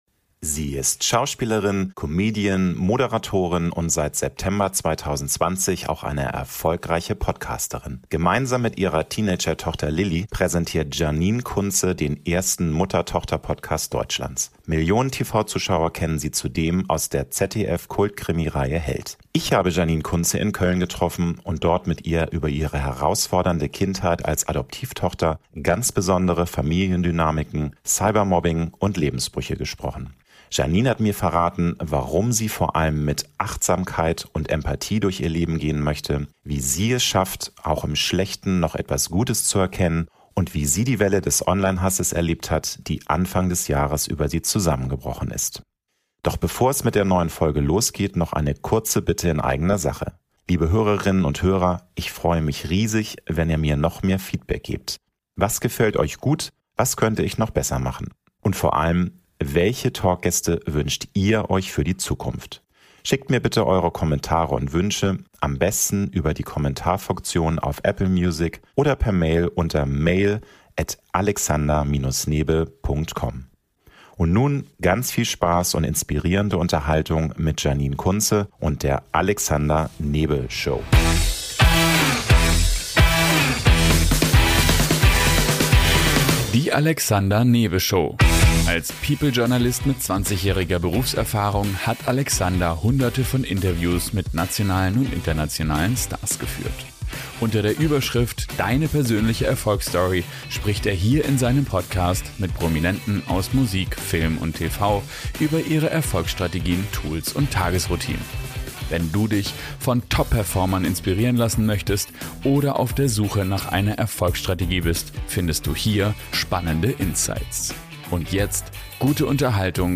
Millionen TV-Zuschauer kennen sie zudem aus der ZDF-Kult-Krimireihe „Heldt“.Ich habe Janine Kunze in Köln getroffen und dort mit ihr über ihre herausfordernde Kindheit als Adoptivtochter, ganz besondere Familien-Dynamiken, Cybermobbing und Lebensbrüche gesprochen. Janine hat mir verraten, warum sie vor allem mit Achtsamkeit und Empathie durch ihr Leben gehen möchte, wie sie es schafft, auch im Schlechten noch etwas Gutes zu erkennen und wie sie die Welle des Online-Hasses erlebt hat, die Anfang des Jahres über sie zusammengebrochen ist.